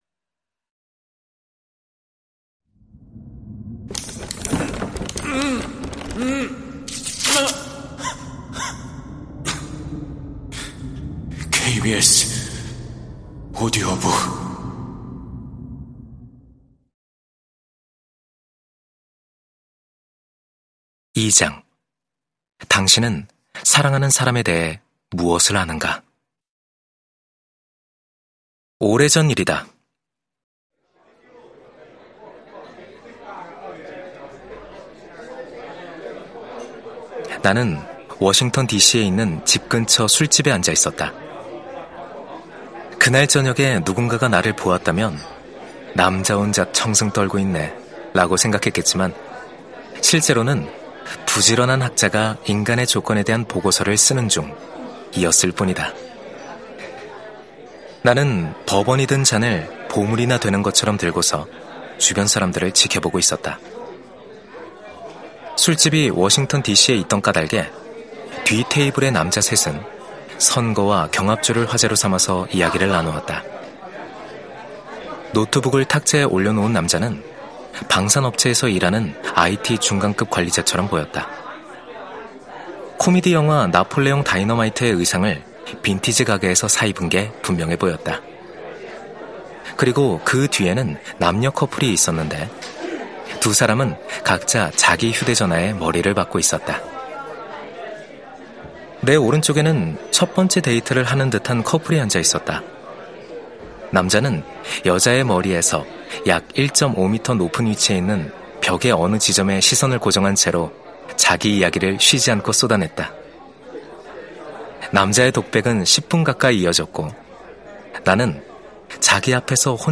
KBS 오디오북 - 최고의 클립